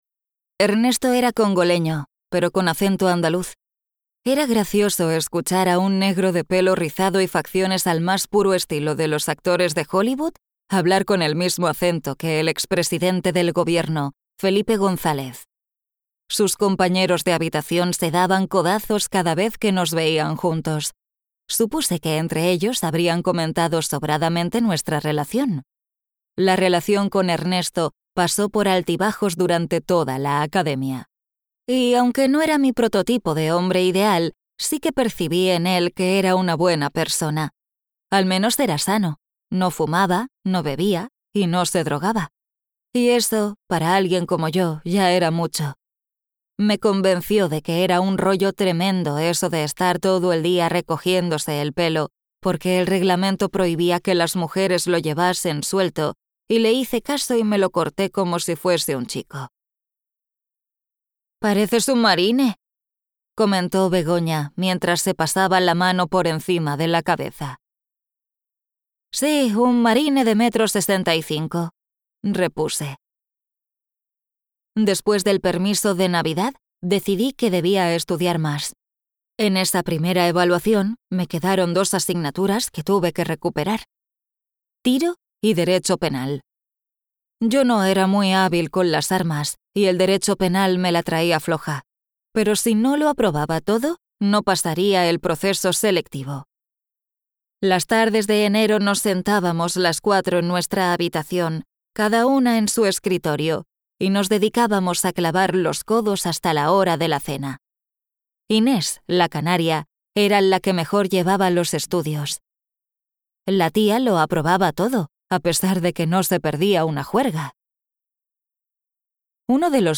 Audiolibros Novela policíaca - Sonolibro